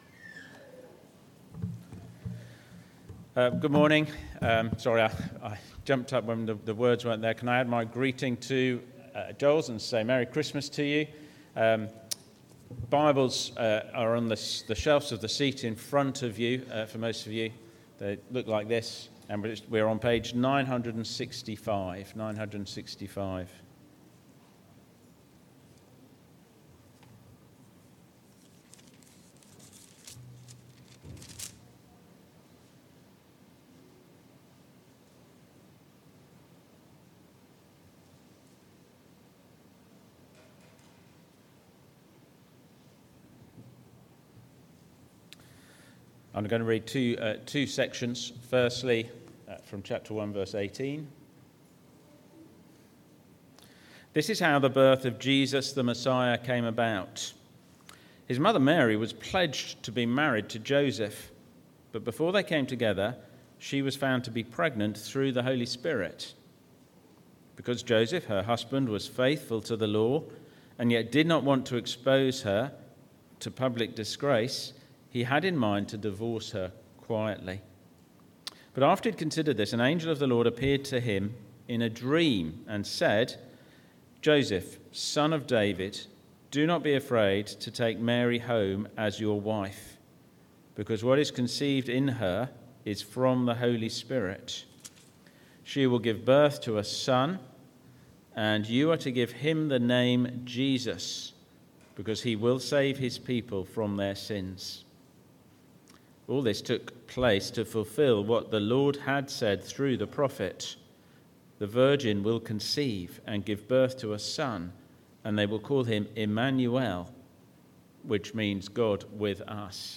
Sermons – Dagenham Parish Church
Service Type: Sunday Morning